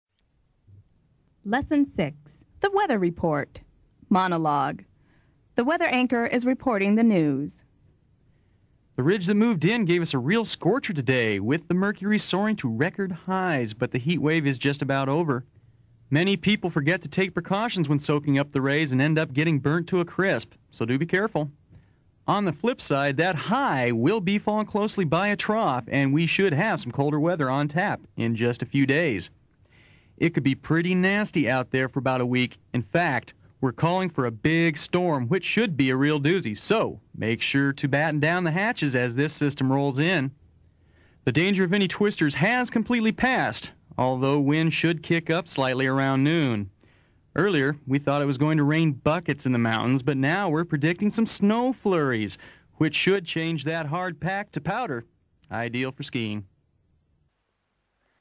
The Weather Report
The weather anchor is reporting the news.